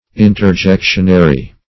Interjectionary \In`ter*jec"tion*a*ry\, a. Interjectional.